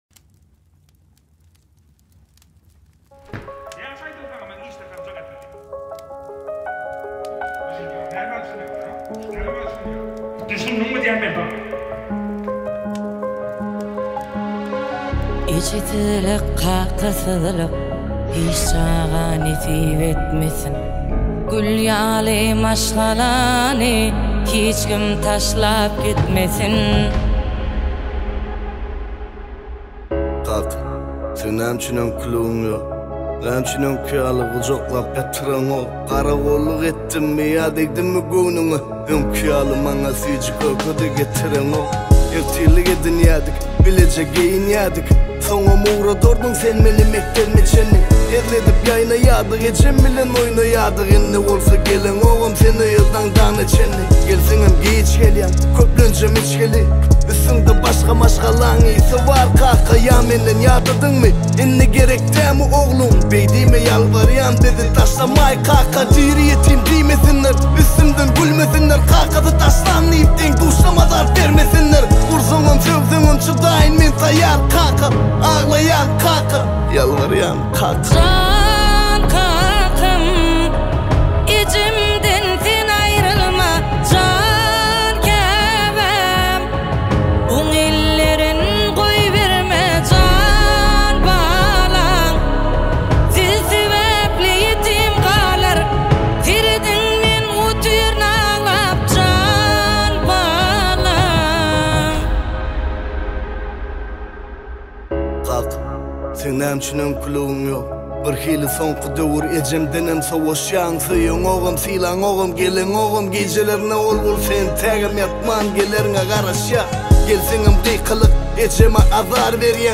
Туркмен музыка